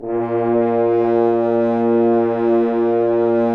Index of /90_sSampleCDs/Roland LCDP06 Brass Sections/BRS_F.Horns 2 _/BRS_FHns Dry _